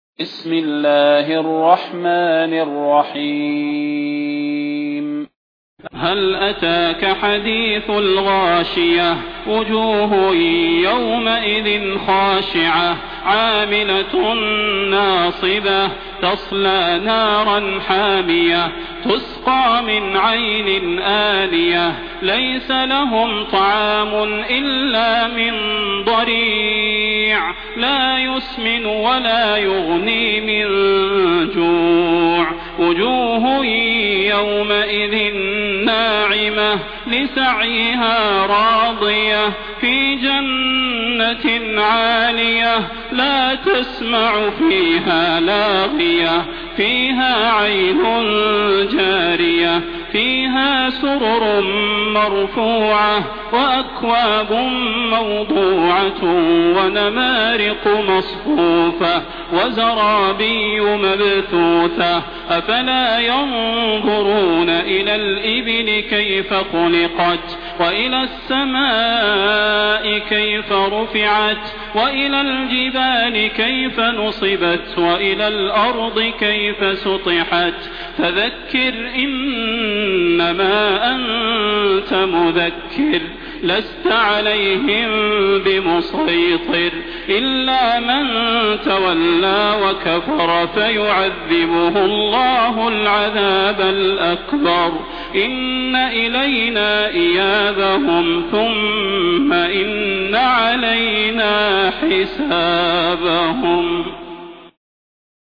المكان: المسجد النبوي الشيخ: فضيلة الشيخ د. صلاح بن محمد البدير فضيلة الشيخ د. صلاح بن محمد البدير الغاشية